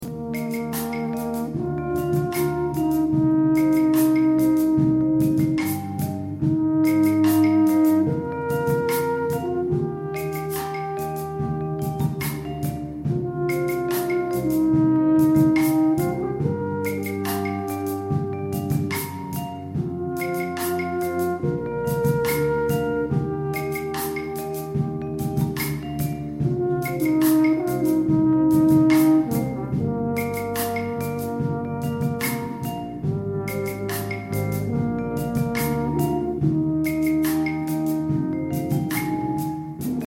Cor Naturel Mib
Cor.mp3